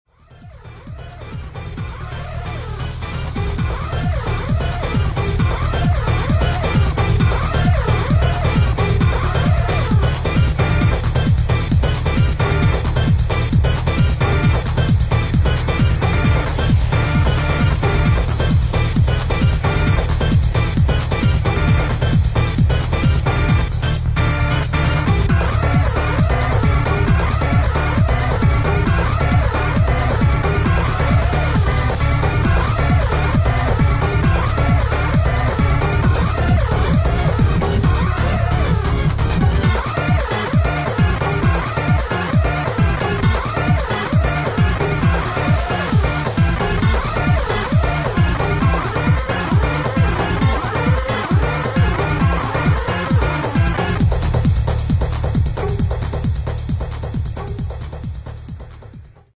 Techno Detroit